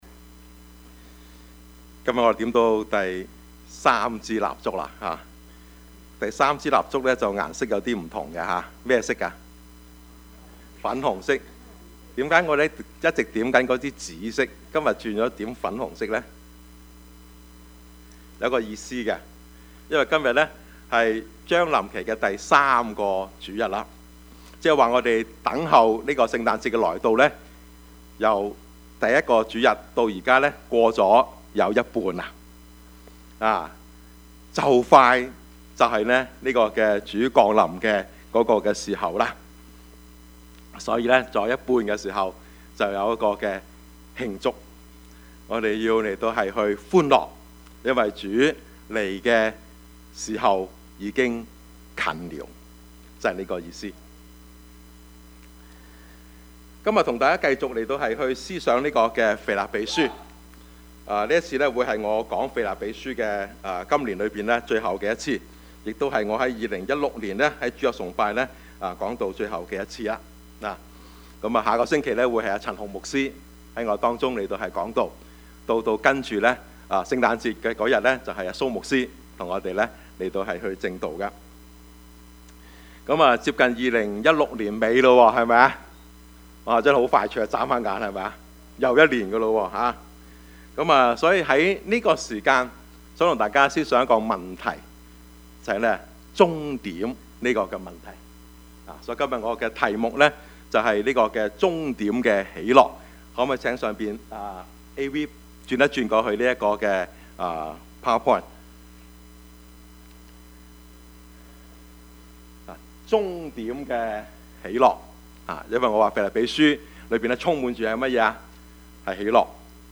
Service Type: 主日崇拜
Topics: 主日證道 « 主已經近了 飲食文化 »